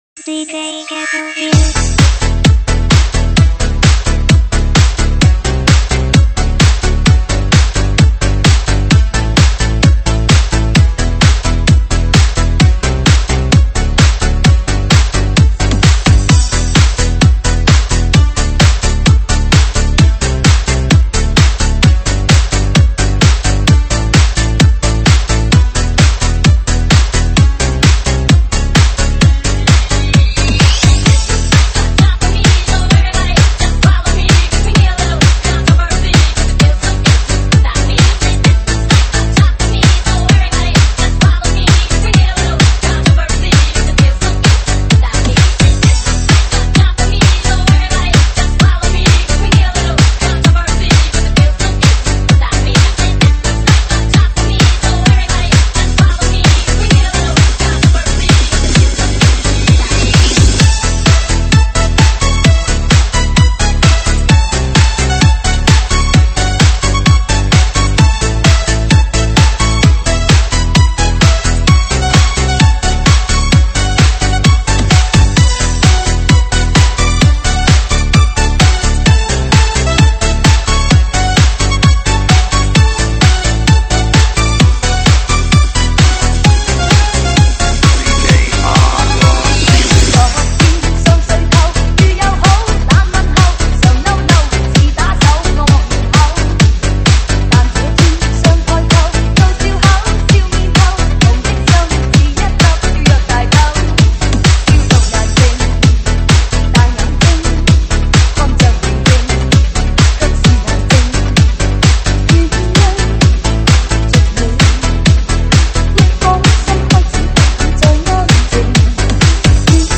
经典 抖音 伤感情歌 暖场